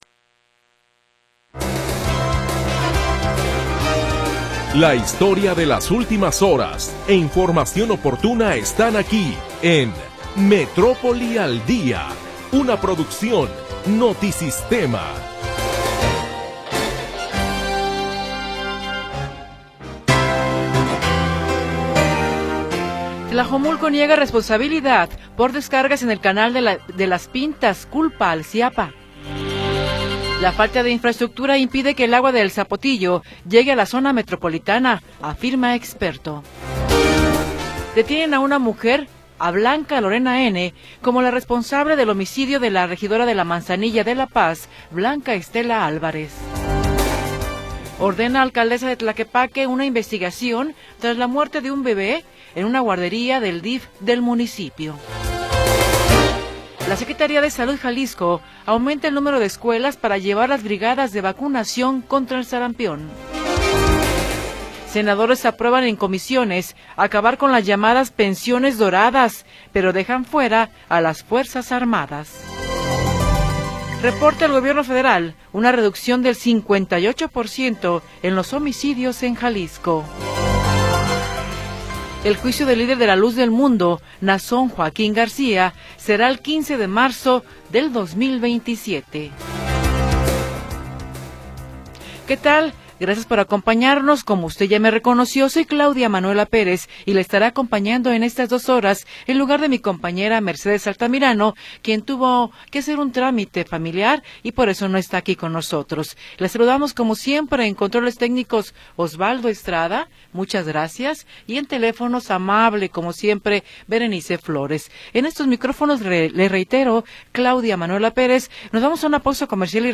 Primera hora del programa transmitido el 10 de Marzo de 2026.